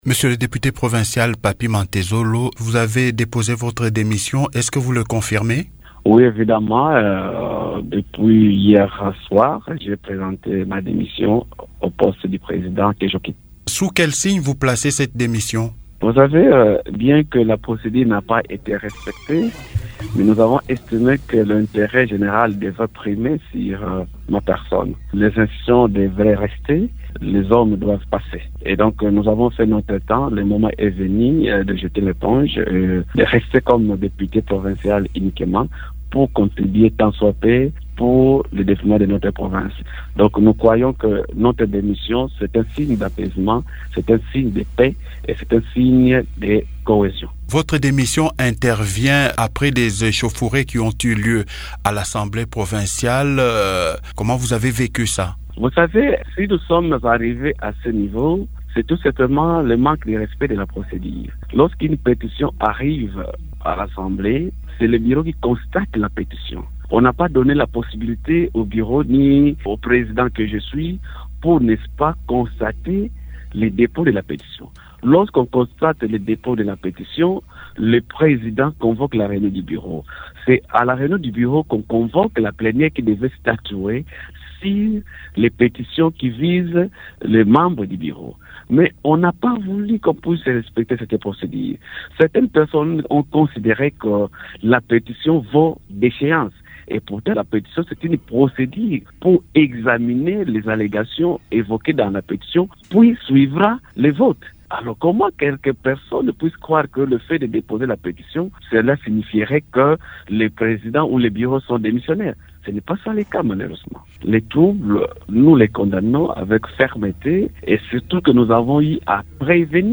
Dans une interview exclusive à Radio Okapi, il explique les raisons qui l’ont poussé à déposer sa démission après les échauffourées enregistrées la journée à l’hémicycle.